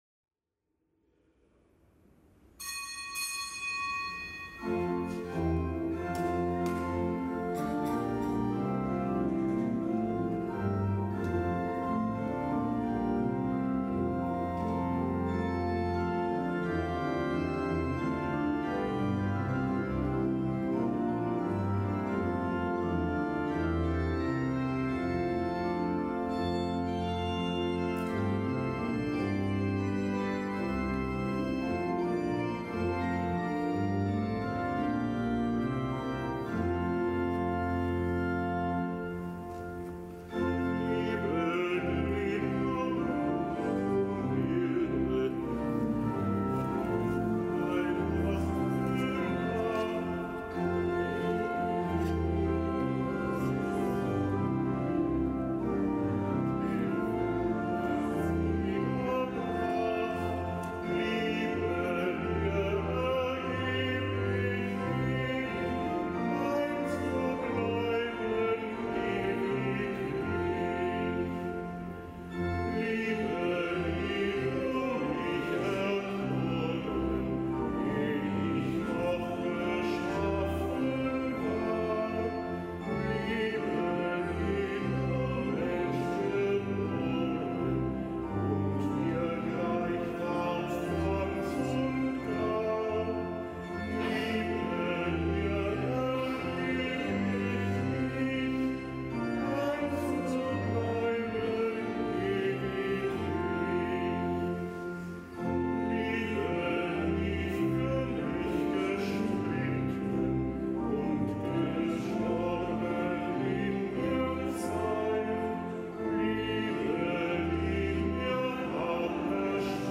Kapitelsmesse aus dem Kölner Dom am Gedenktag des Heiligen Maximilian Maria Kolbe, einem Ordenspriester und Märtyrer der römisch-katholischen und der griechisch-katholischen Kirche.